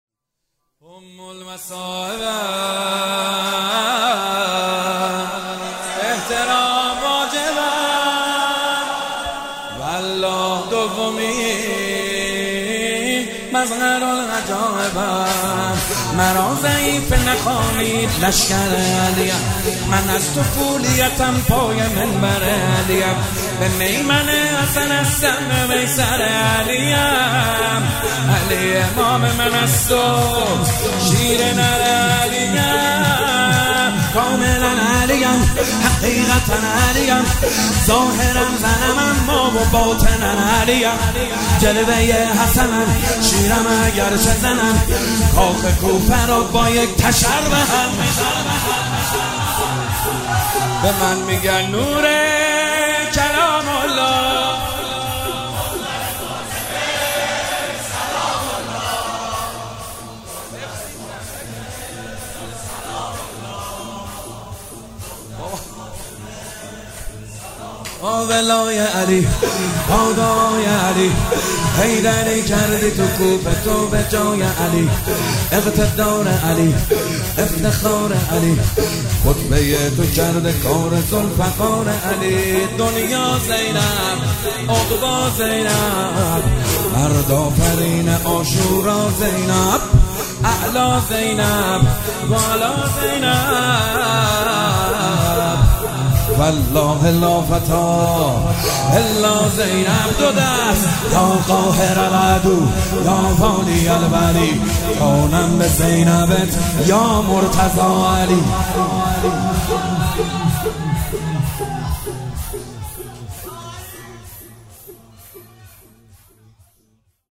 شهادت حضرت زینب شور ام المصایبم محمد حسین حدادیان